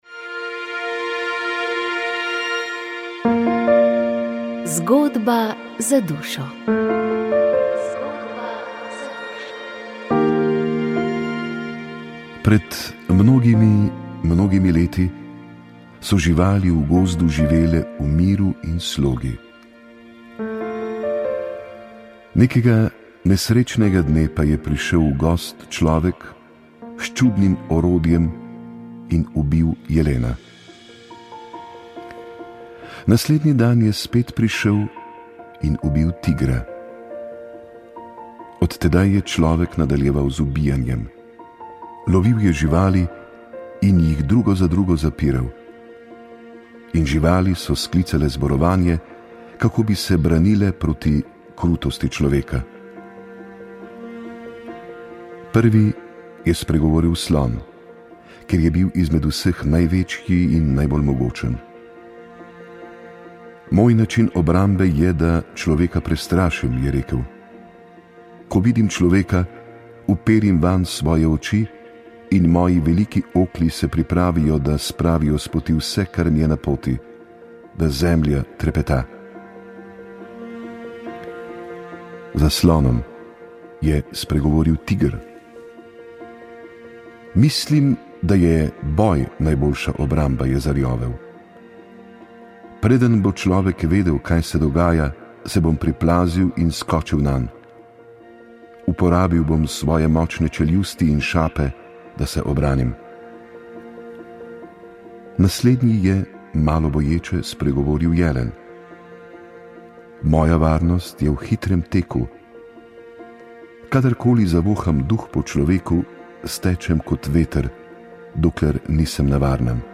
Sveta maša za domovino iz stolnice svetega Nikolaja v Ljubljani
Maševanje je vodil nadškof metropolit Stanislav Zore.